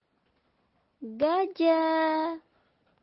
gajah.mp3